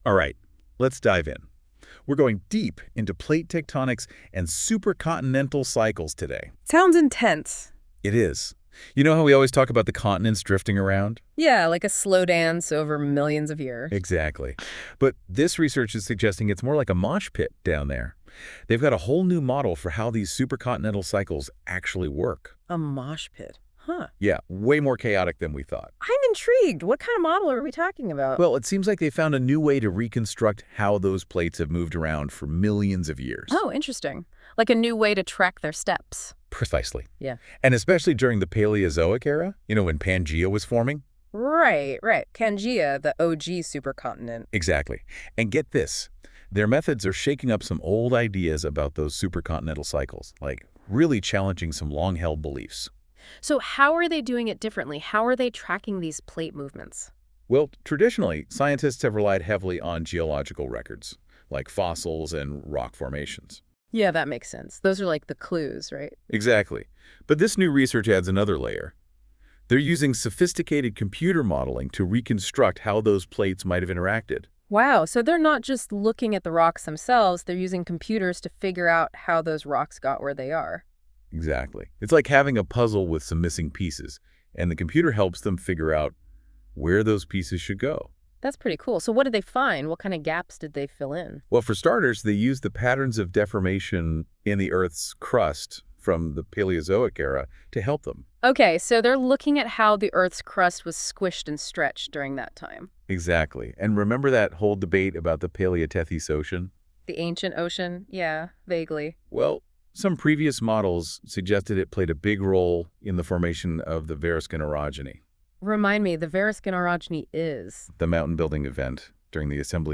Audio AI-generated[^1] audio summary